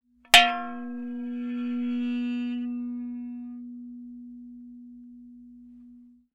Metal_55.wav